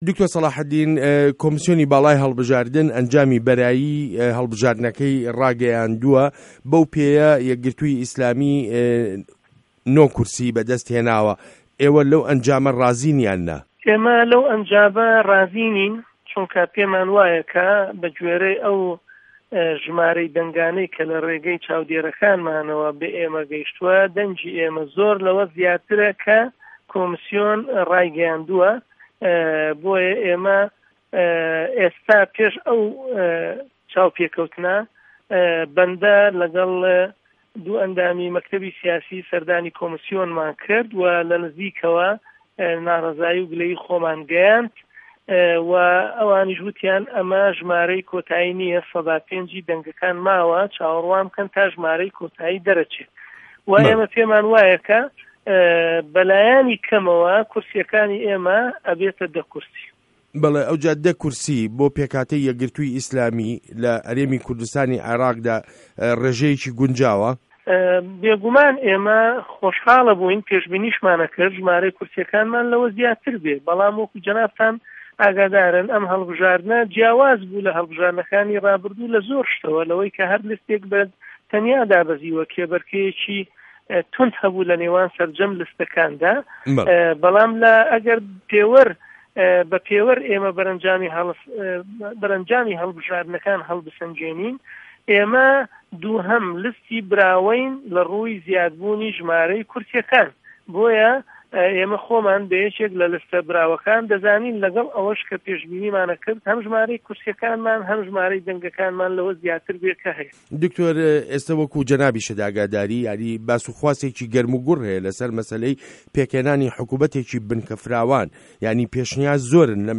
وتووێژ له‌گه‌ڵ دکتۆر سه‌ڵاحه‌دین بابه‌کر